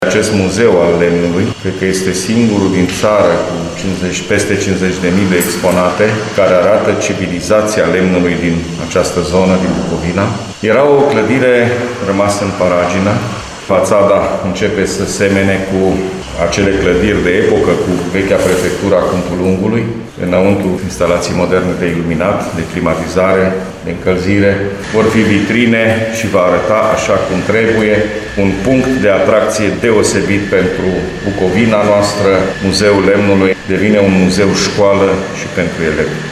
Investițiile în valoare de 2 milioane 700 mii euro sunt realizate cu fonduri europene, după cum a precizat președintele Consiliului Județean Suceava GHEORGHE FLUTUR.